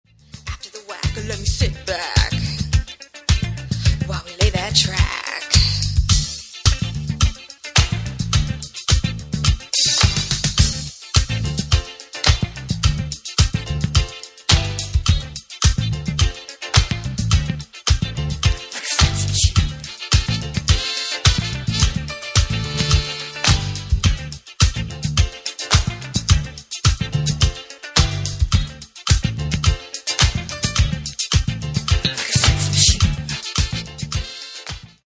Genere: Funk | Soul
12''Mix Extended